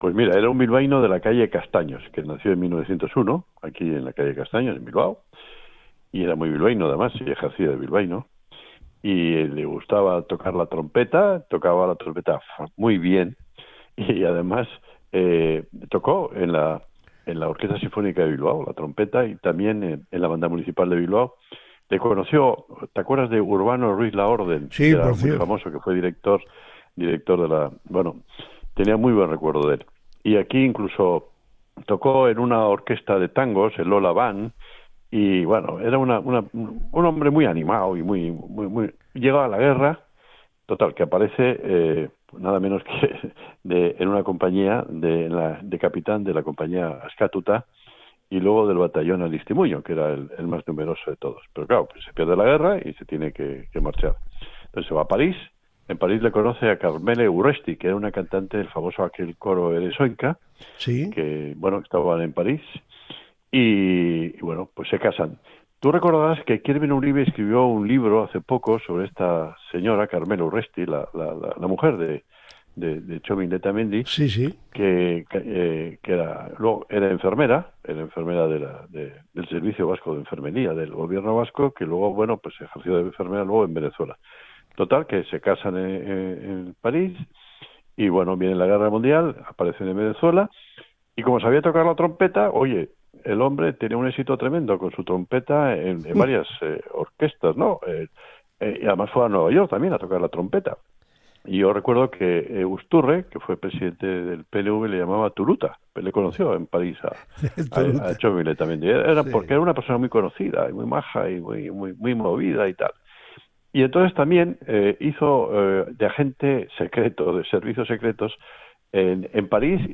Iñaki Anasagasti nos presenta a este singular personaje de la historia de Bilbao
ENTREVISTA-INAKI-ANASAGASTI-28-FEBRERO-TROMPETISTA-ESPIA-Copia.mp3